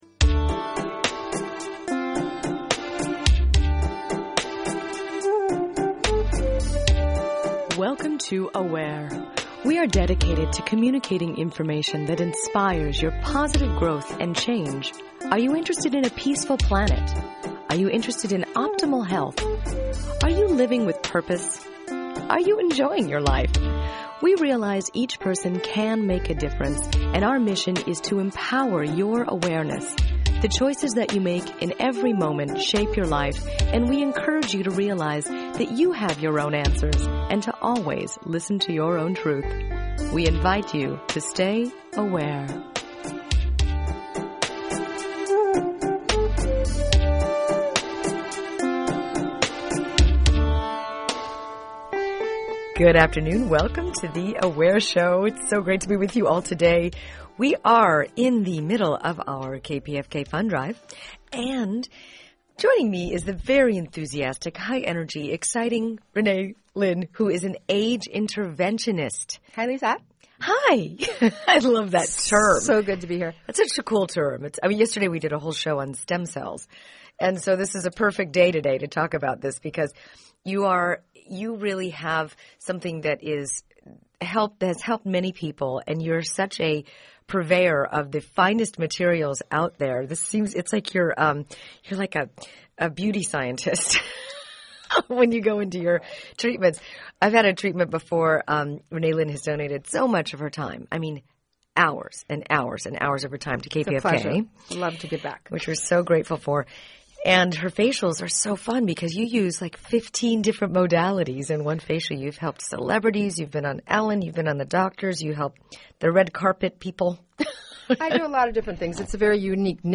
Do something good for yourself (or a loved one) AND KPFK by pledging during this special fund drive show.